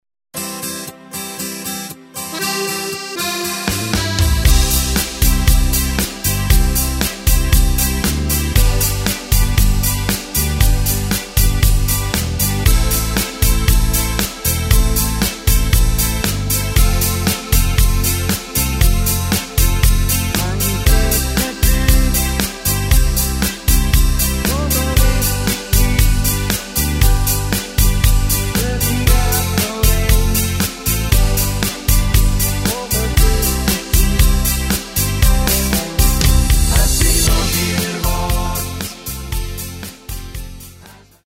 Takt:          4/4
Tempo:         117.00
Tonart:            C
Schweizer Schlager aus dem Jahr 2025!
mp3 Playback mit Chor